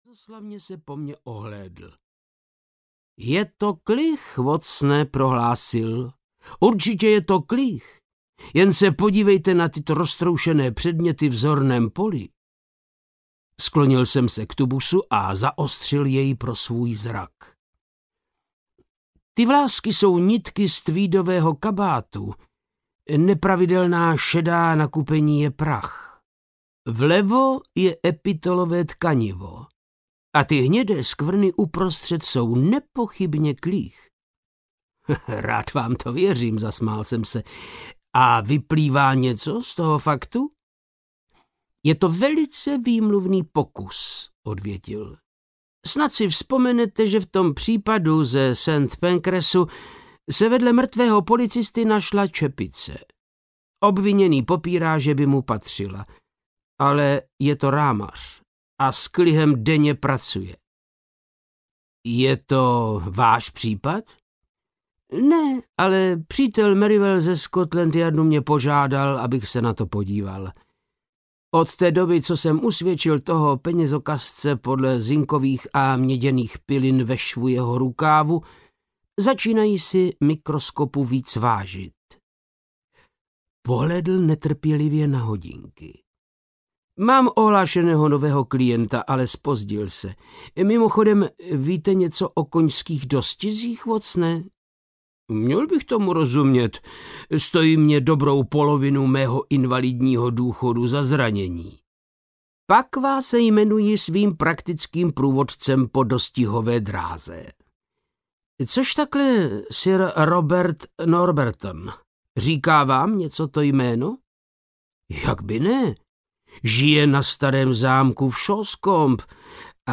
Na starém zámku v Shoscombe audiokniha
Ukázka z knihy
• InterpretVáclav Knop